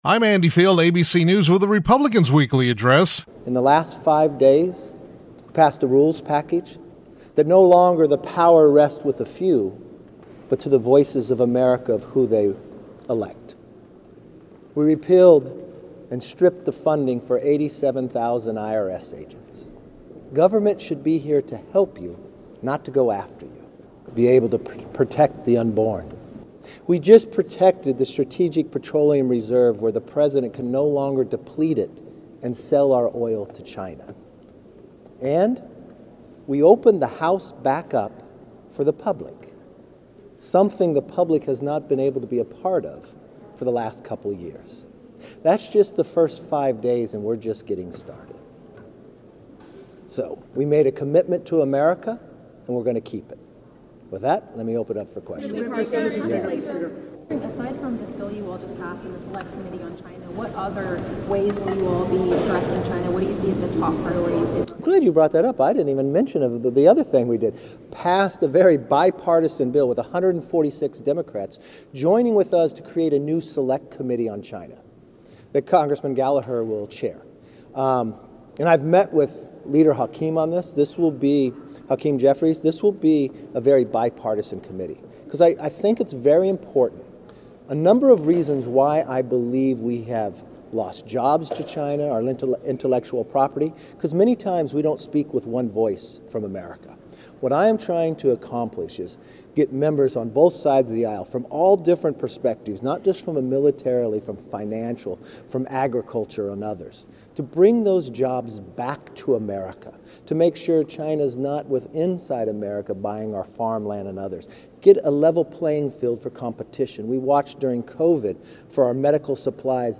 Speaker Kevin McCarthy (CA-20) delivered remarks on the House floor where he called on his colleagues to stand up to the Chinese Communist Party by voting to establish a Select Committee on China.